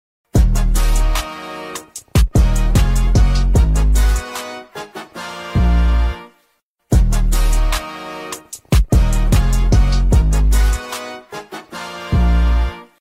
Portable Electric Juicer USB Charging sound effects free download
Portable Electric Juicer USB Charging Fruit Mixers Smoothie Bullet Blender